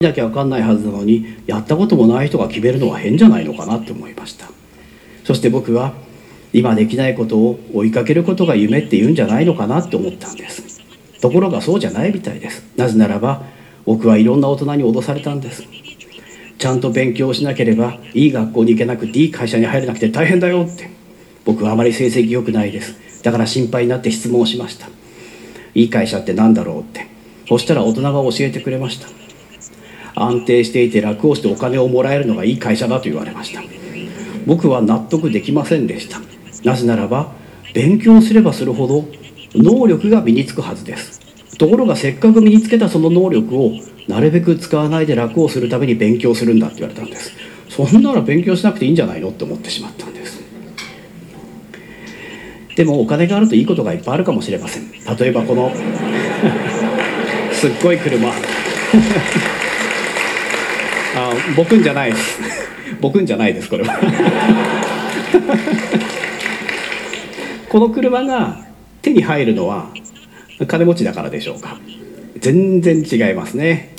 録音データでは、イヤホンからの通訳音声が裏でかすかに聴こえるので、注意して聴いてみてほしい。